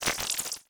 Futuristic Sounds (26).wav